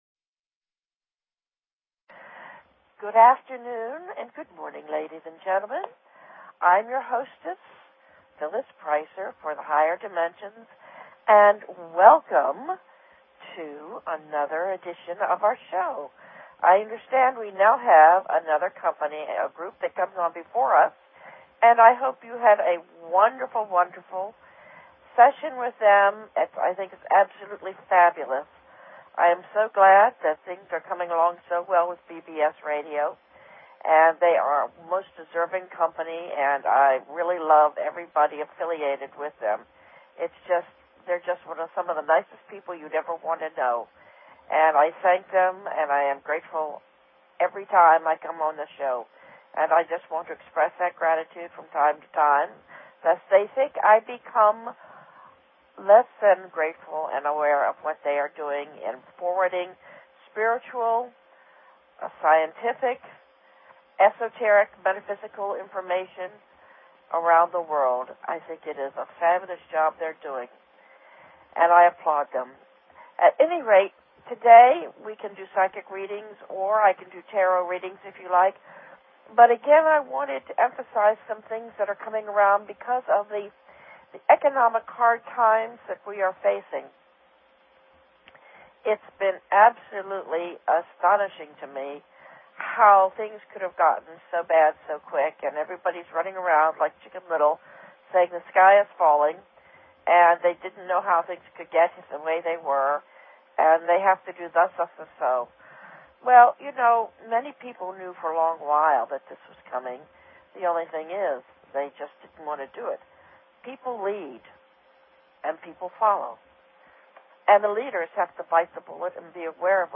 Talk Show Episode, Audio Podcast, Higher_Dimensions and Courtesy of BBS Radio on , show guests , about , categorized as